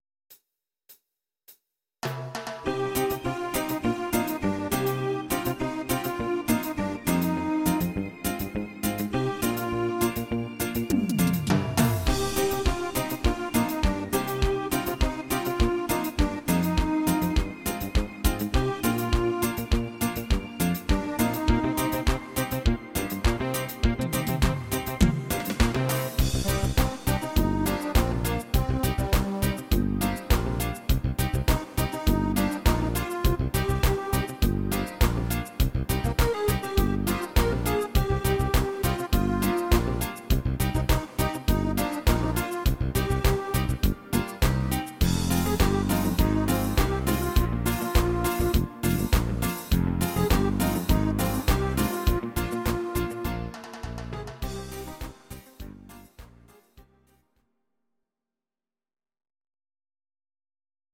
Audio Recordings based on Midi-files
Pop, Dutch, 1980s